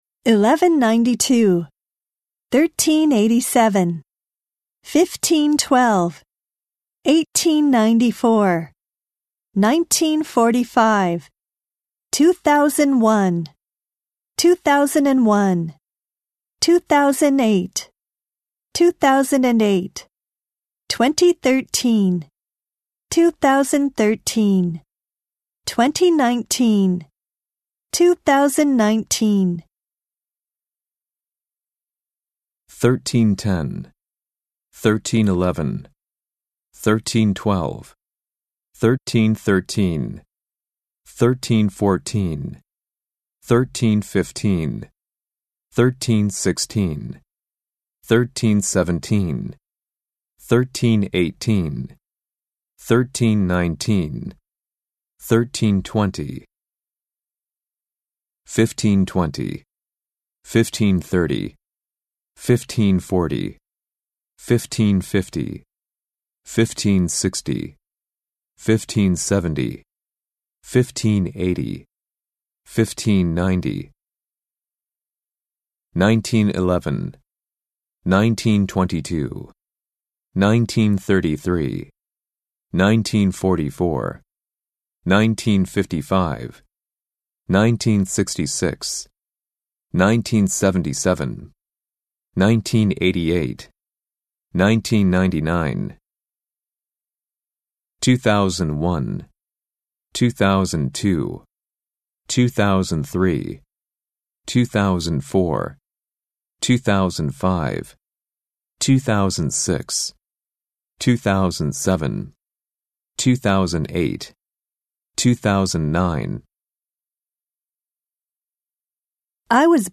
・ナレーター：アメリカ英語のネイティブ２名（男女）
・スピード：ナチュラル